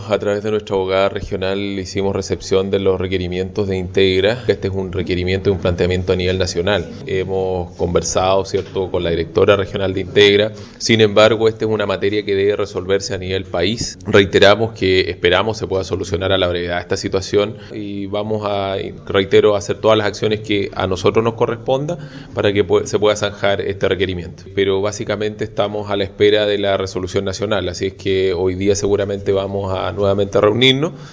Por su parte, el intendente de Los Ríos, Cesar Asenjo, aseguró que se mantienen en diálogo con la dirección regional y están a la espera de la resolución emanada desde la mesa central de negociación.